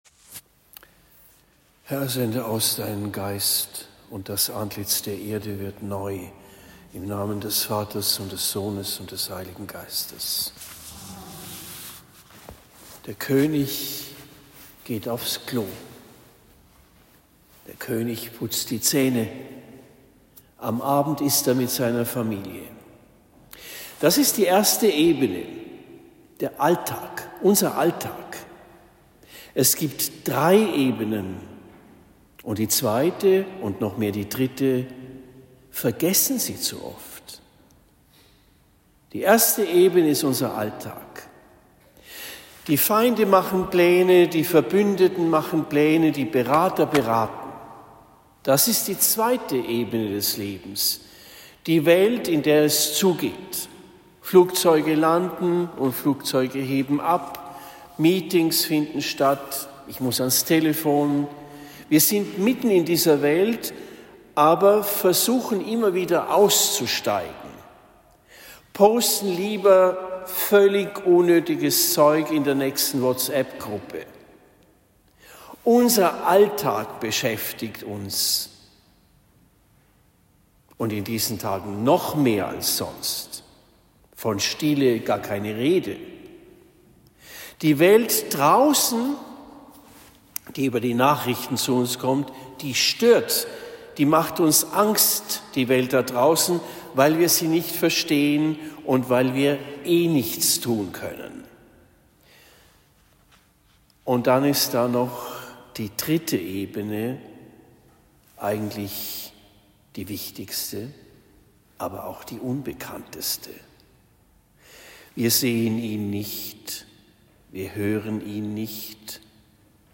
Predigt in Zimmern am 21. Dezember 2025